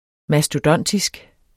Udtale [ masdoˈdʌnˀtisg ]